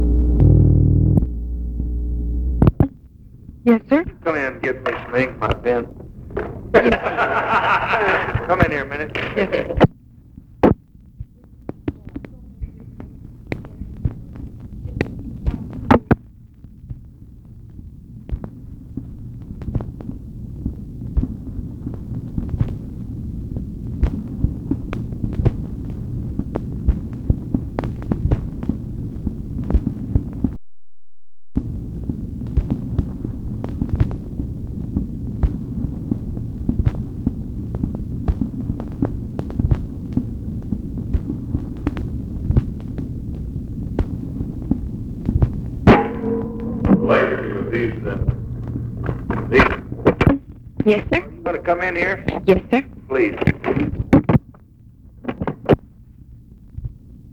Conversation with OFFICE SECRETARY, February 7, 1964
Secret White House Tapes